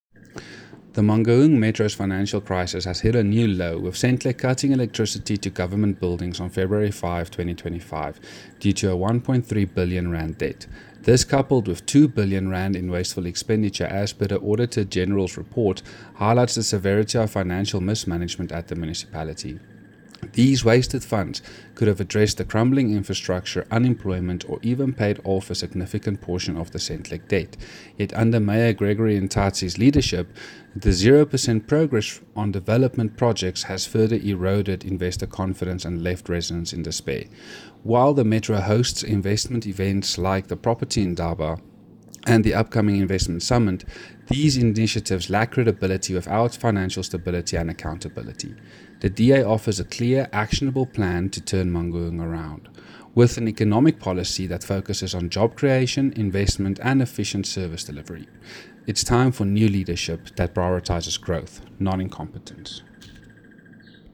Afrikaans soundbites by Cllr Andre Snyman and